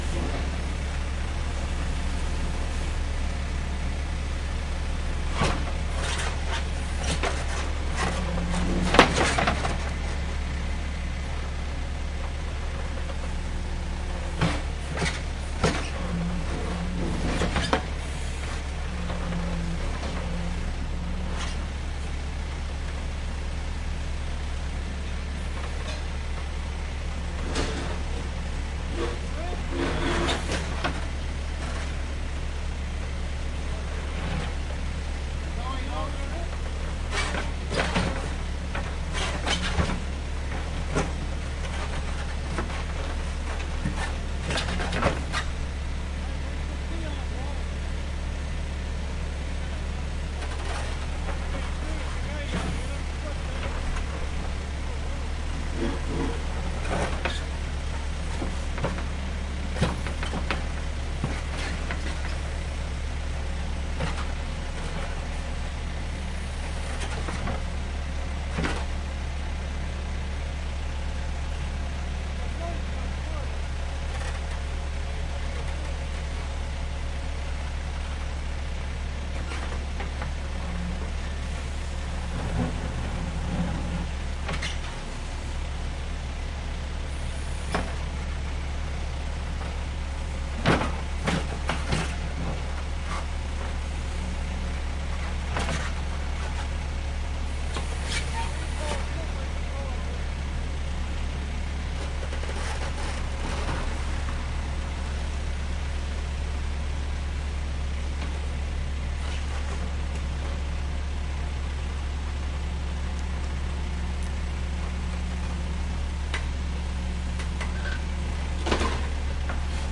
随机 " 建筑用反铲挖掘街道，将人行道上的块状物铲入垃圾车2 加拿大蒙特利尔
描述：建筑反铲挖掘街道铲子路面大块到转储truck2蒙特利尔，Canada.flac
Tag: 挖掘 卡车 施工 路面 向上 街道 锄耕机